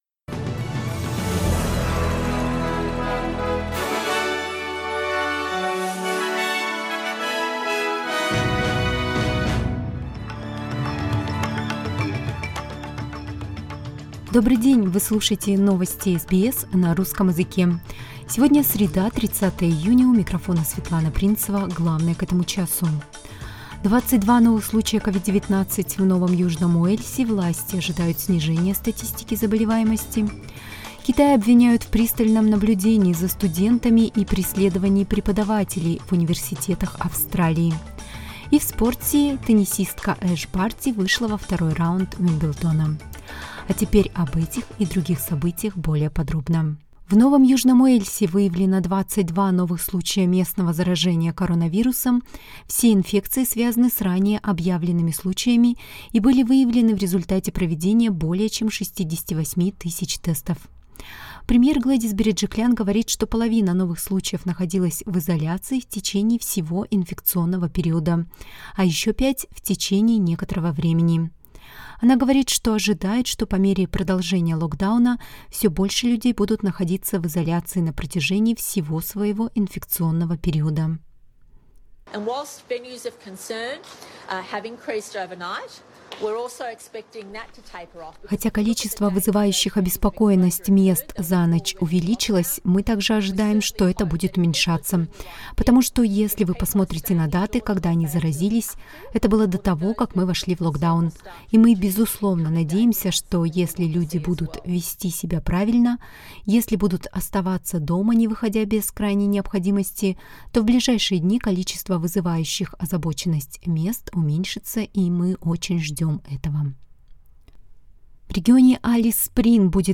SBS News in Russian, June 30